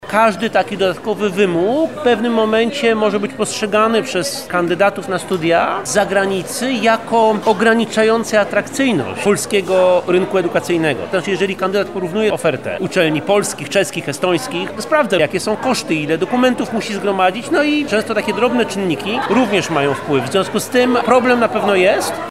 Konferencja pod tytułem „Studenci zagraniczni w Lublinie – potencjał doświadczeń i nowe perspektywy”, która odbyła się 5 grudnia na Wydziale Politologii i Dziennikarstwa UMCS była polem do dyskusji na temat wad i zalet przyjmowania obcokrajowców przez polskie uczelnie.